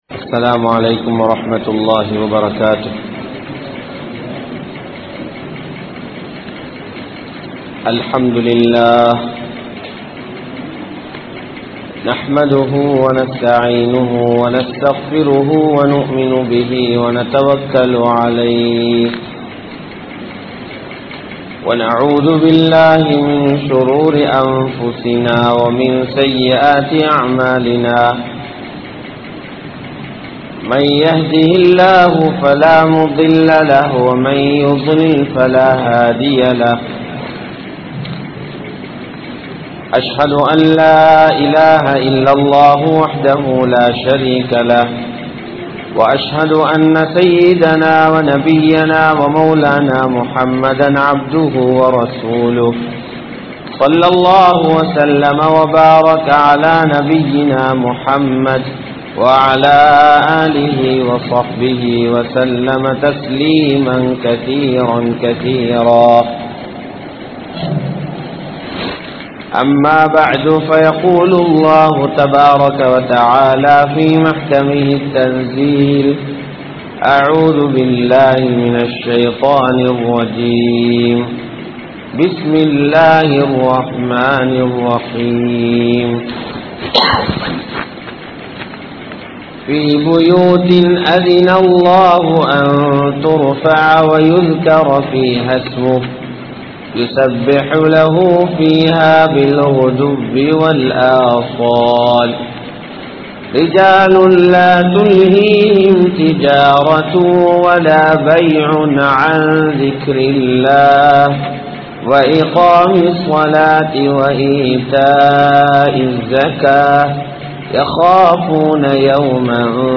Unmaiyaana Selvantharhal Yaar? (உண்மையான செல்வந்தர்கள் யார்?) | Audio Bayans | All Ceylon Muslim Youth Community | Addalaichenai
Kurunegala, Weharabanda Jumua Masjidh